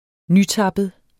Udtale [ ˈnyˌtɑbəð ]